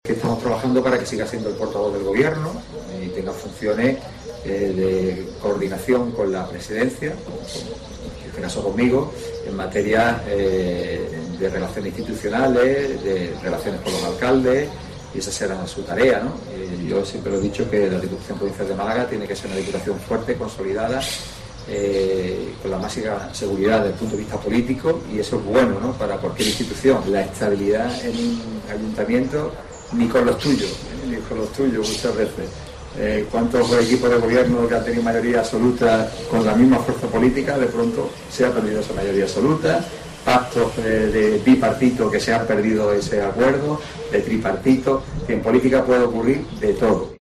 Francis Salado habla sobre el acuerdo con Cassá.
Francisco Salado es presidente de la Diputación y habló ante los medios preguntado por este asunto, “estamos trabajando para que siga siendo el portavoz del Gobierno y tenga funciones de coordinación con la presidencio, conmigo en este caso y en materia de relaciones institucionales y con los alcaldes, esa será su tarea, la Diputación Provincial de Málaga tiene que ser fuerte, de consolidación y fuerte desde el punto de vista político, y eso es bueno para cualquier institución”, subrayó Salado.